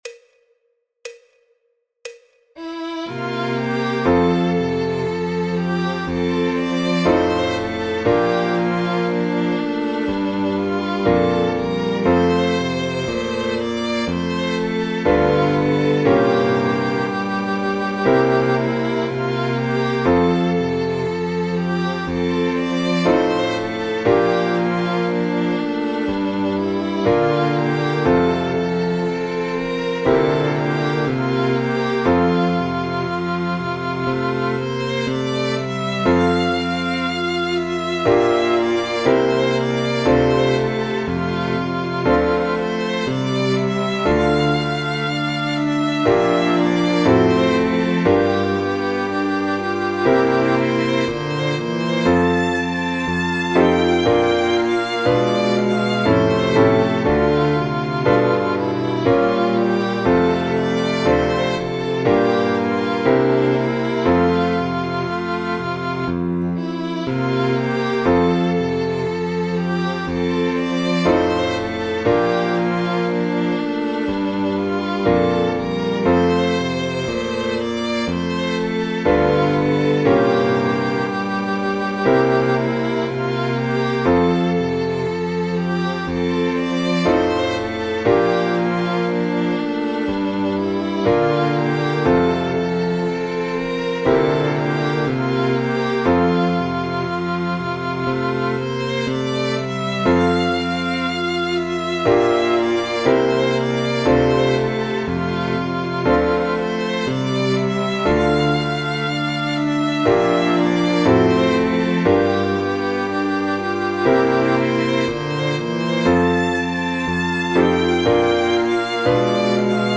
Disponible Partitura PDF MIDI MP3 y KARAOKE para Violín.
El MIDI tiene la base instrumental de acompañamiento.